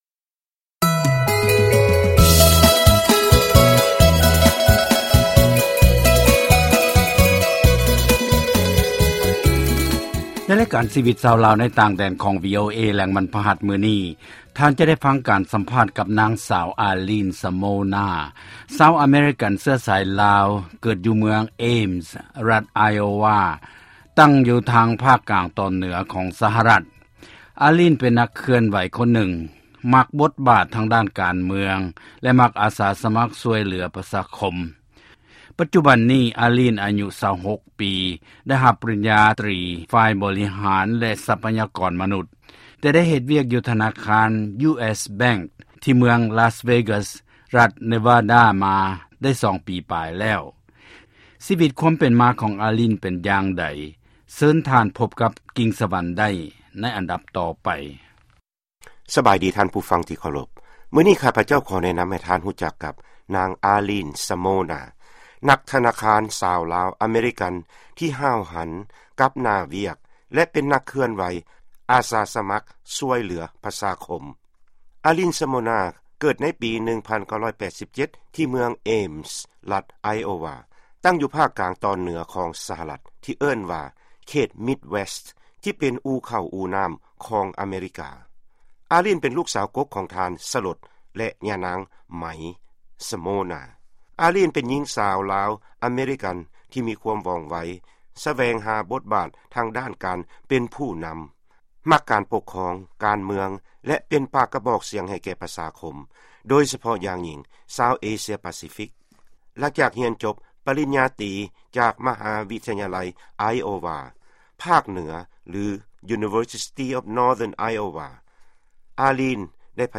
ຟັງລາຍການສຳພາດ